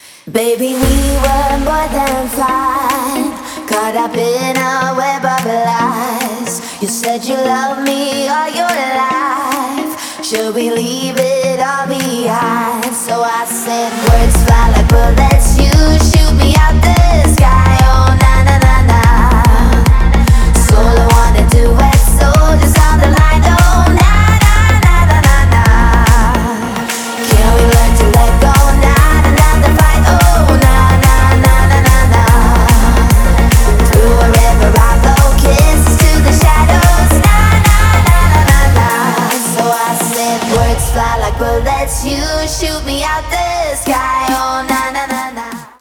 поп
зажигательные
красивый женский голос